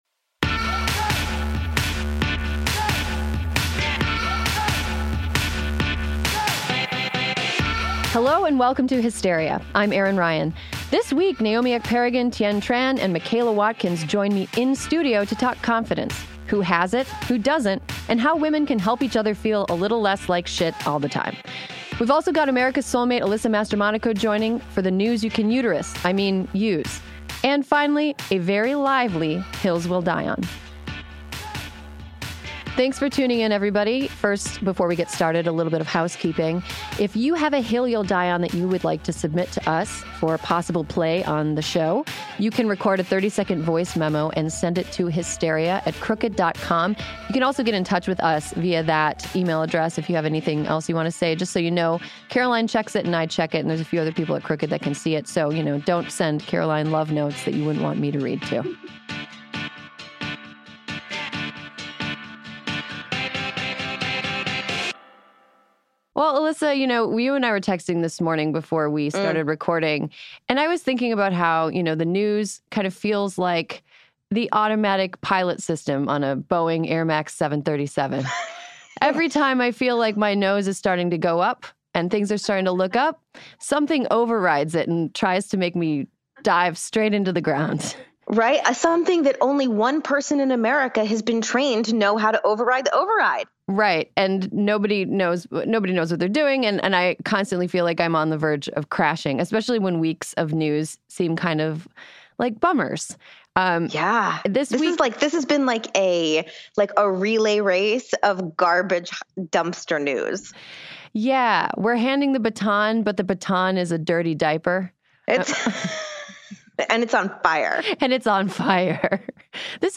Plus Alyssa Mastromonaco calls in to discuss the GOP’s never-ending war against the uterus.